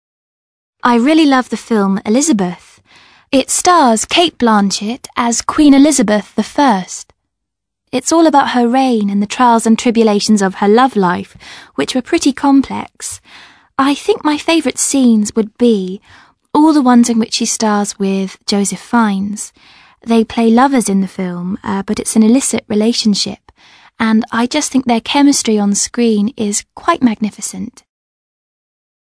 ACTIVITY 59: You are going to listen to a second woman taking about a historical film she has seen.